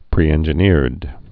(prēĕn-jə-nîrd)